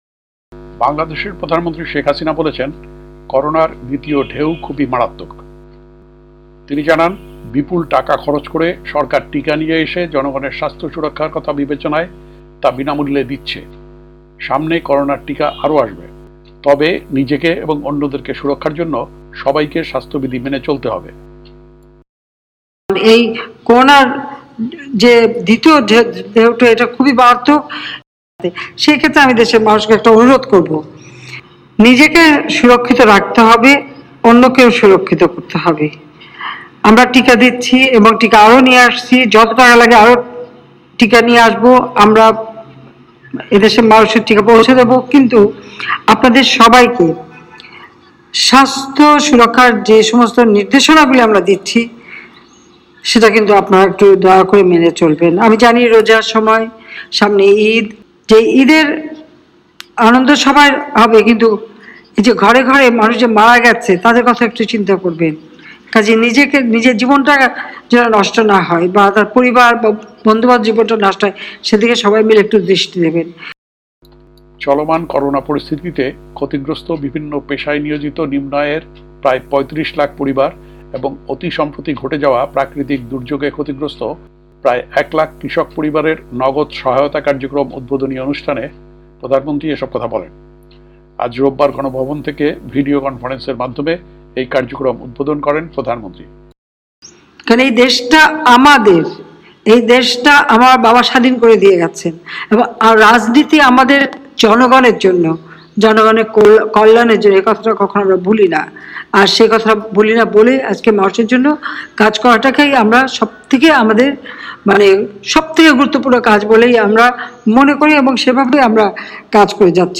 আজ (রোববার) গণভবন থেকে ভিডিও কনফারেন্সে এ কার্যক্রম উদ্বোধন করেন প্রধানমন্ত্রী।